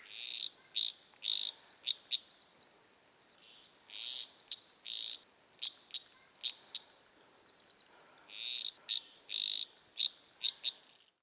Eastern Sedge Frog (Litoria fallax) from NE Queensland.
Both species have a call that consists of one or two "chuck" sounds and an upward slurred raspy trill.  The trill sounds like a fingernail over a very delicate comb.
Here's the Eastern Sedge Frog's (L. fallax) call -
Eastern Sedge Frog calls
So the Eastern Sedge Frog calls trill-chuck-chuck (or trill-chuck).